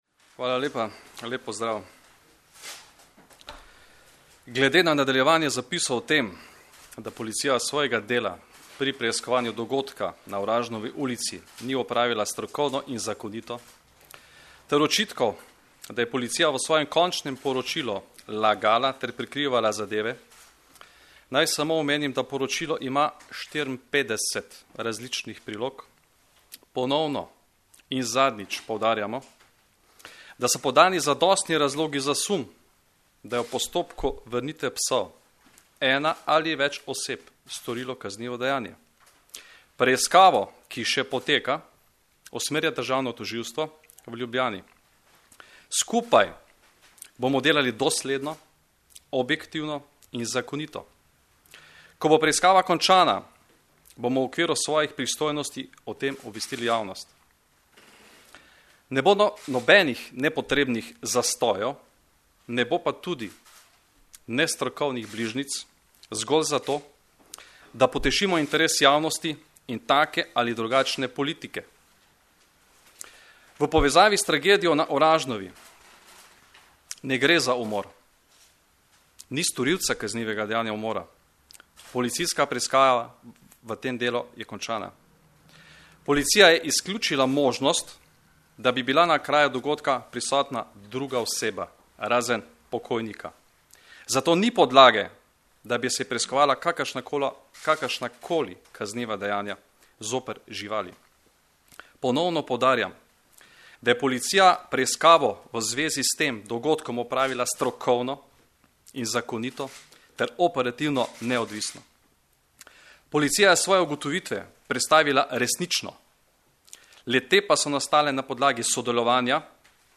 Zvočni posnetek izjave Janka Gorška (mp3)